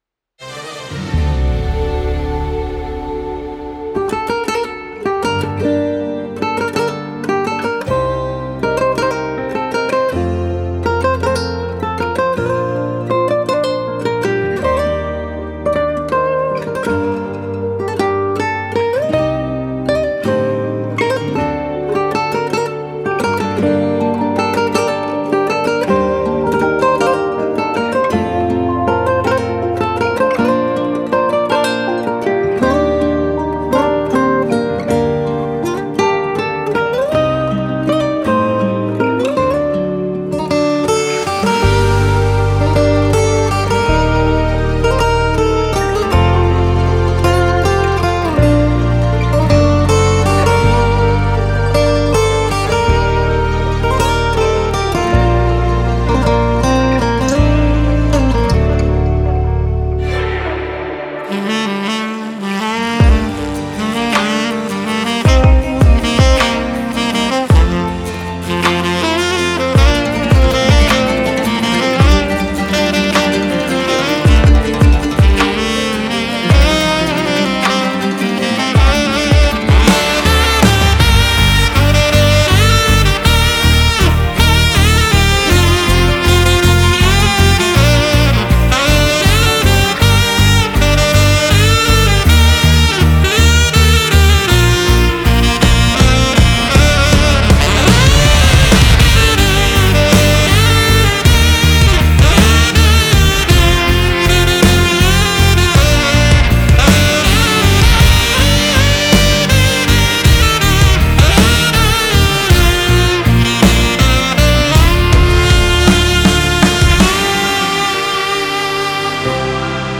יש לי שאלה קטנה, אני אצרף קטע של איזה מפיק מוזיקה אלקטרונית, והוא משחק שמה עם הצליל בצורה מגניבה, ואני לא מבין כל כך בזה, ומעוניין לדעת איך משחקים עם הצליל בצורה כזו, האם זה אפקט מסויים, או אופן נגינה?